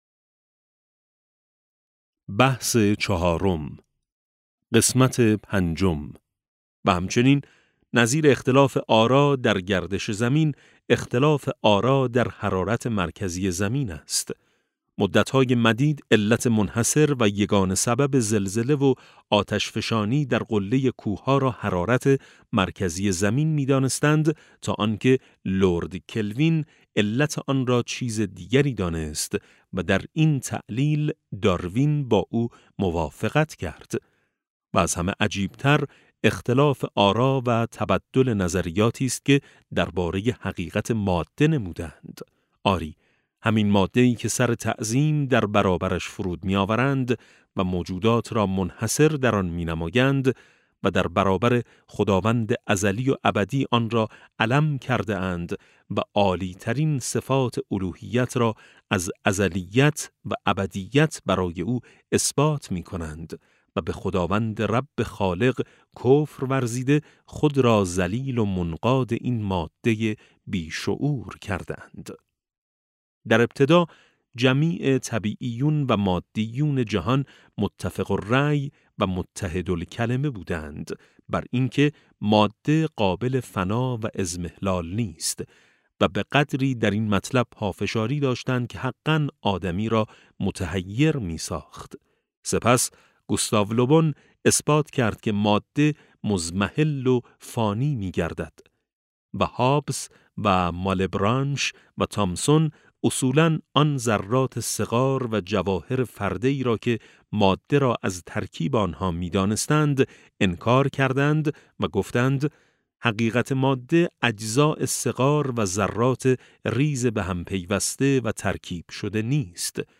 کتاب صوتی نور ملکوت قرآن - ج2 - جلسه6